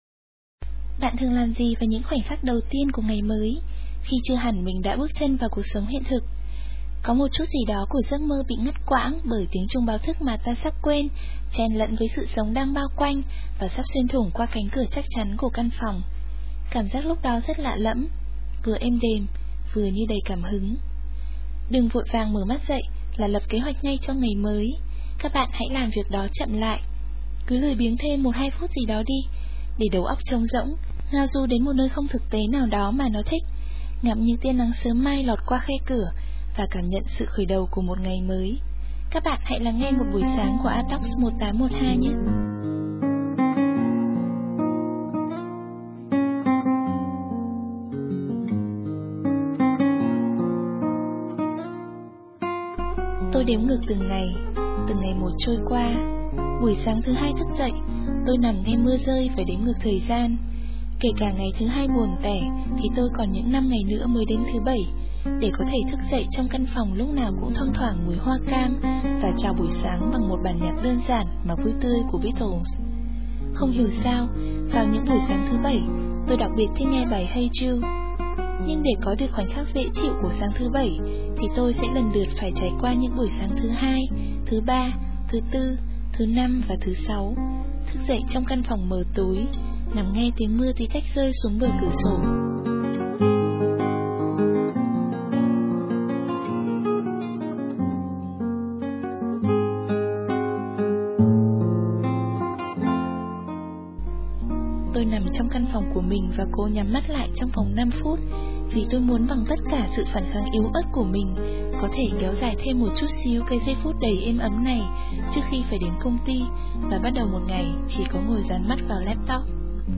Và, nhân dịp, file lưu trong máy tôi bị hỏng, tôi làm lại chương trình Sự lặng im với một chút xíu thay đổi về lời dẫn, âm nhạc và chất giọng không còn nhão nhoẹt như lần trước nữa Phải đến hơn nửa năm nay tôi mới lại động đến đống “đồ nghề” của mình.